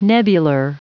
Prononciation du mot nebular en anglais (fichier audio)
Prononciation du mot : nebular